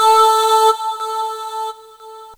voiTTE64007voicesyn-A.wav